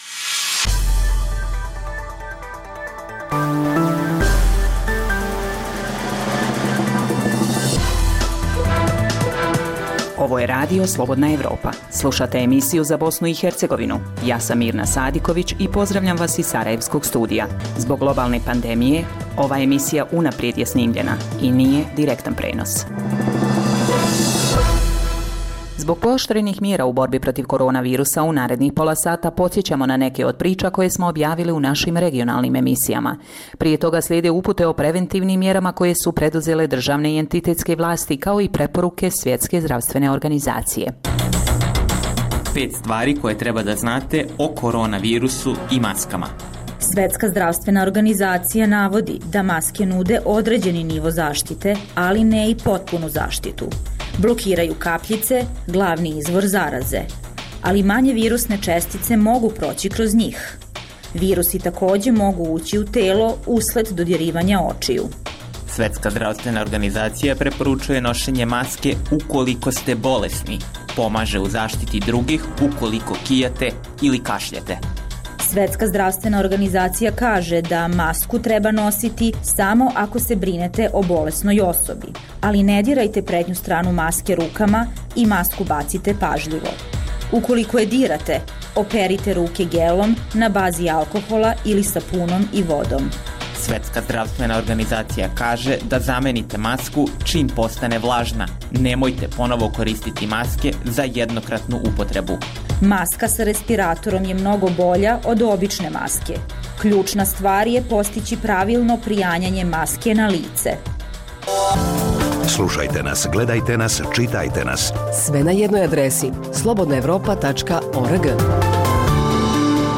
Zbog pooštrenih mjera u cilju sprječavanja zaraze korona virusom, ovaj program je unaprijed snimljen. Poslušajte neke od priča koje smo objavili u regionalnim emisijama